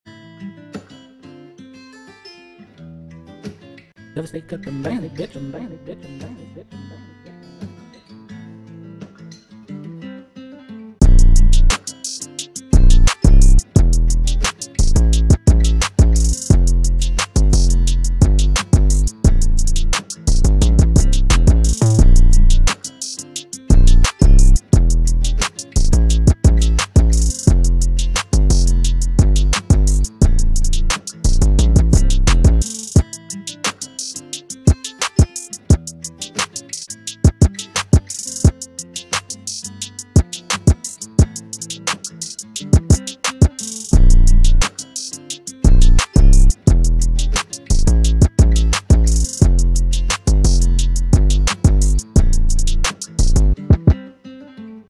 Trap Type Beat